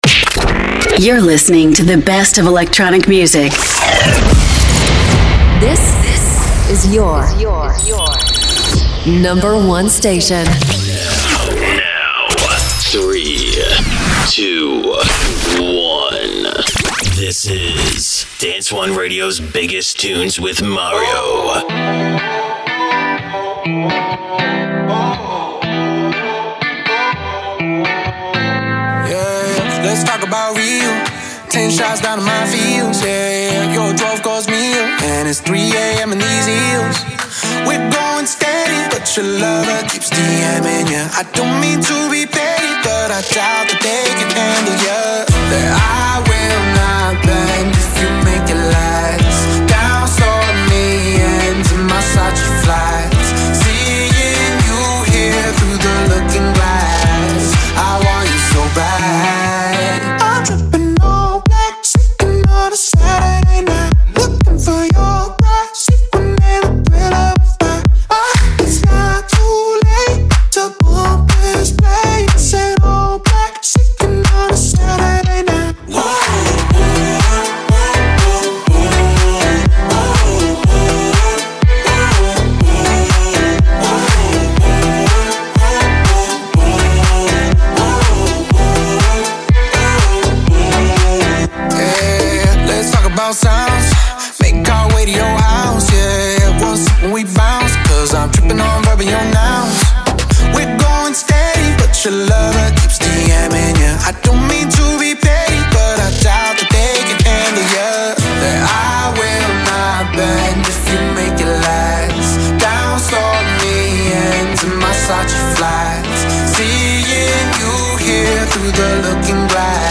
Dance anthems that rules the dance and electronic scene